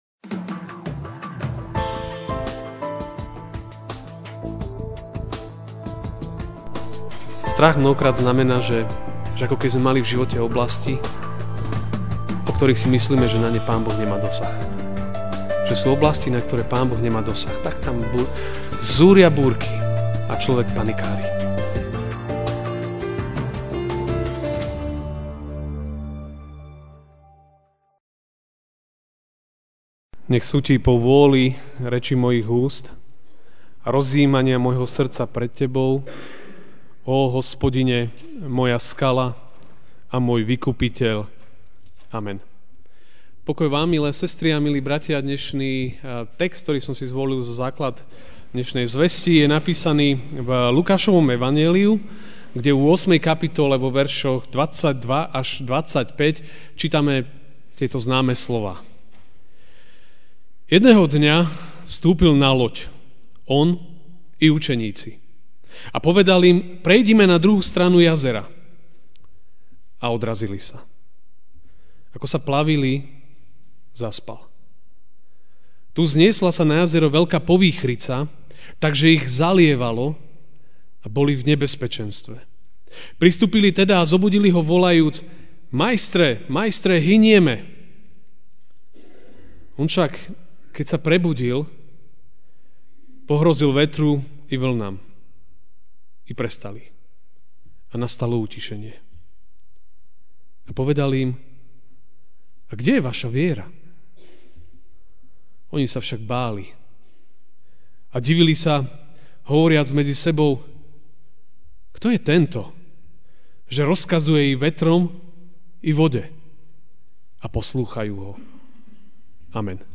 Ranná kázeň: Slovo, ktoré utíši každú búrku!